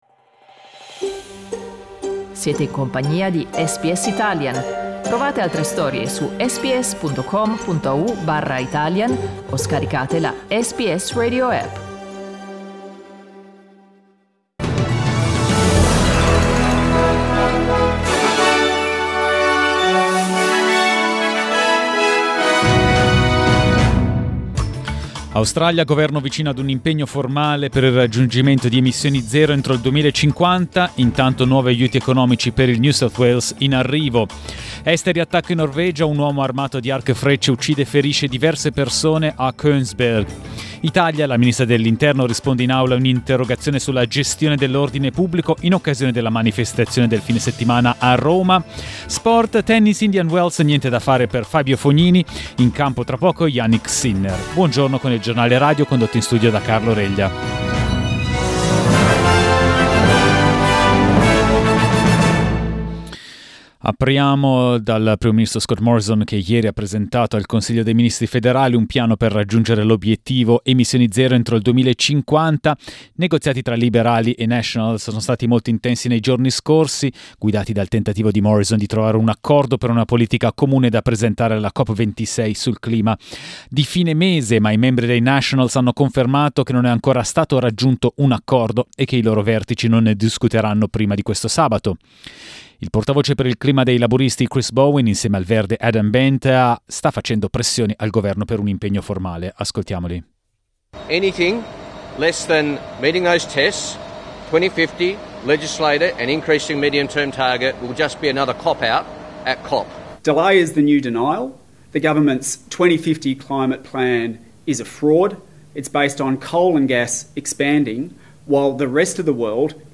Giornale radio giovedì 14 ottobre 2021
Il notiziario di SBS in italiano.